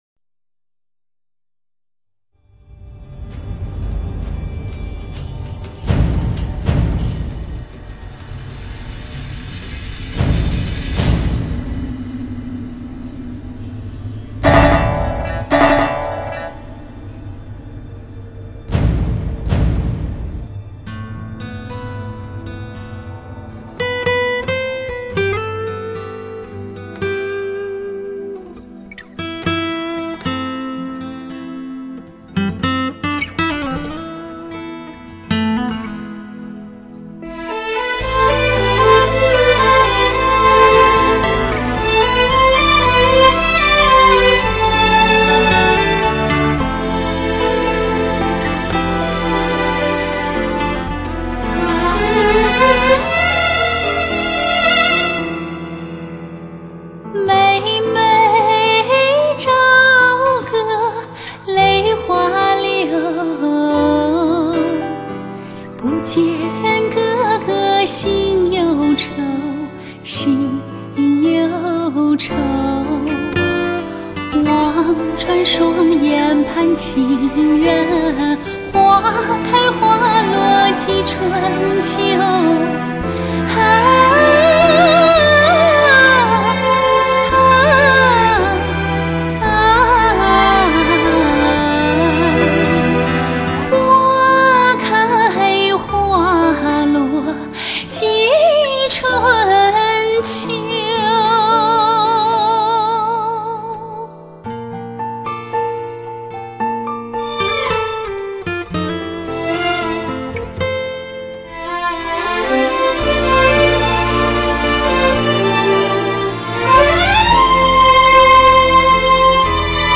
音质还行。
音质不错，谢谢分享！